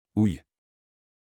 Houilles (French pronunciation: [uj]